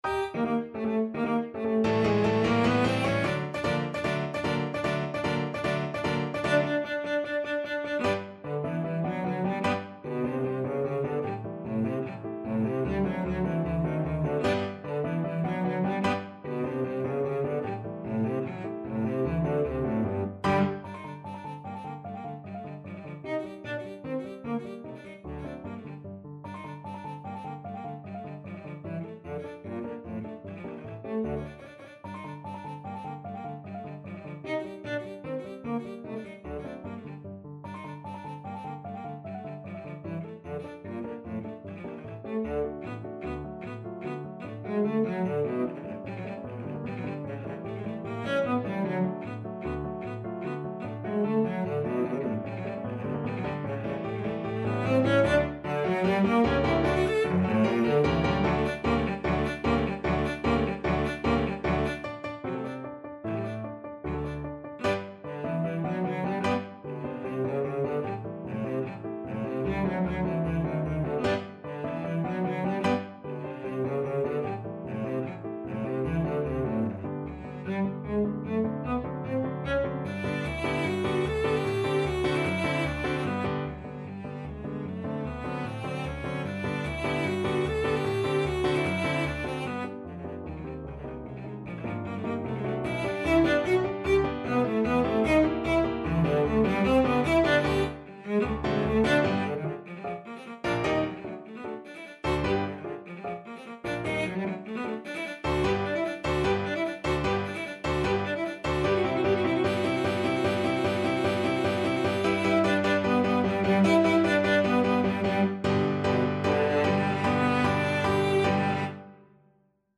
Cello
2/4 (View more 2/4 Music)
G major (Sounding Pitch) (View more G major Music for Cello )
Allegro vivacissimo ~ = 150 (View more music marked Allegro)
Classical (View more Classical Cello Music)